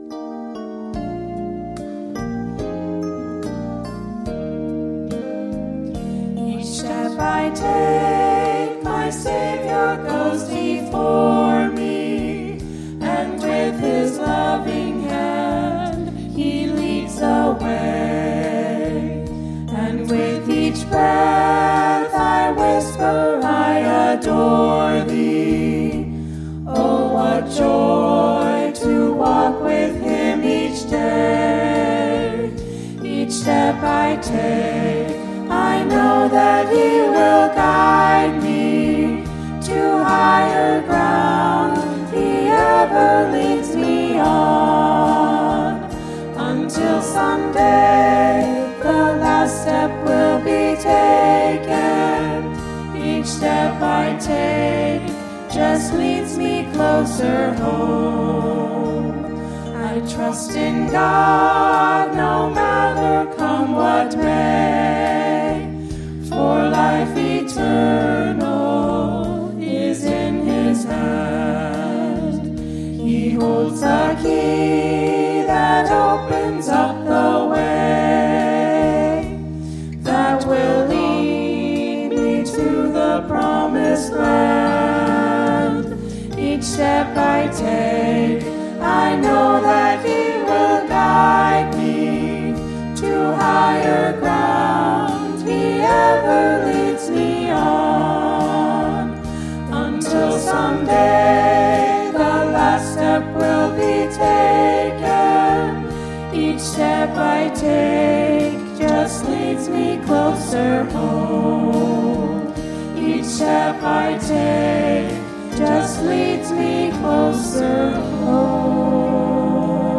Sunday Morning Music
Special Music